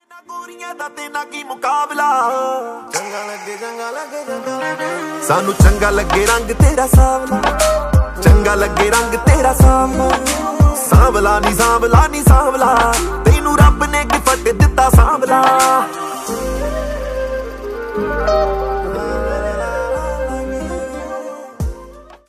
Punjabi sang